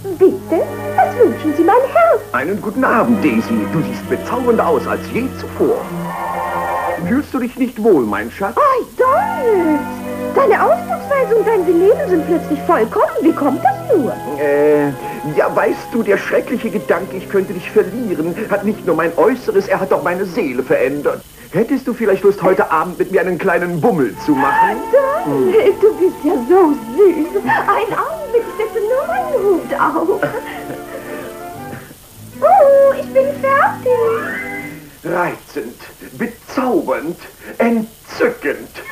Die sehr schlechte Tonqualität könnte natürlich an der VHS-Kassette liegen, aber für mich klingt das absolut nach 60er-Kinosynchro